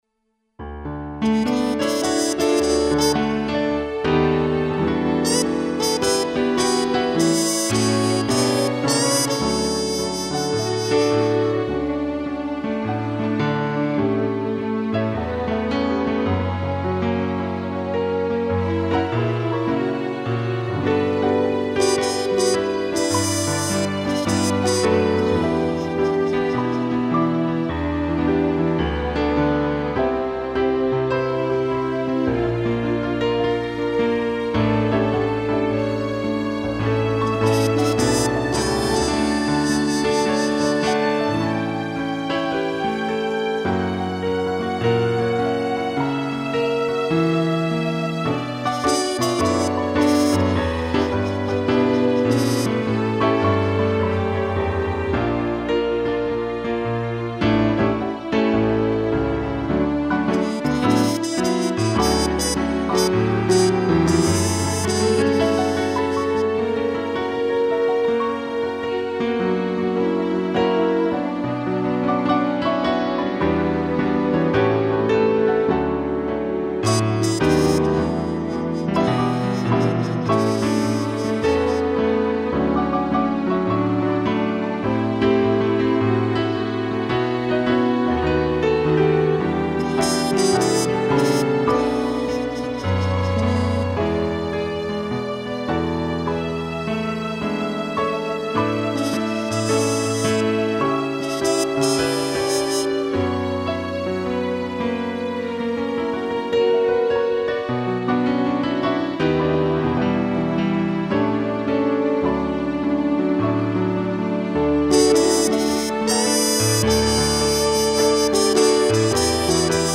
2 pianos, sax e strings
(instrumental)